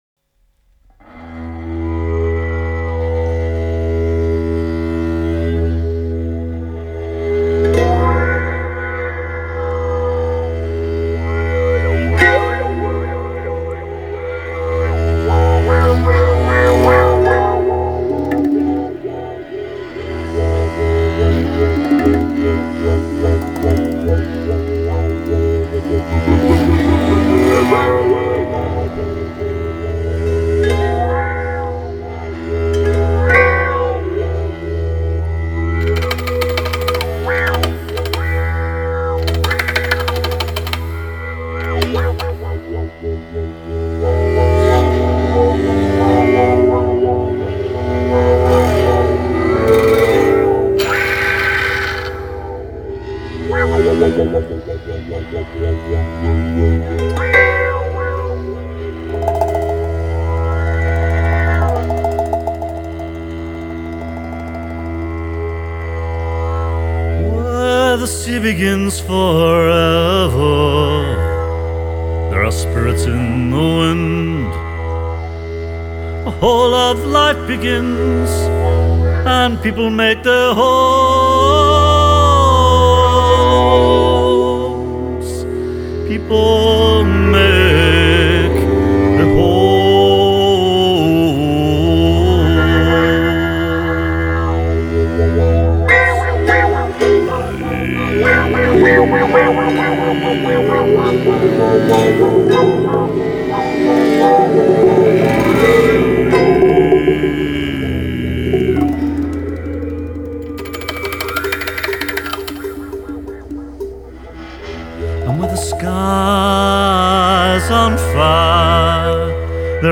Gendung (Indonesian Drums)
Xylopt and drum kit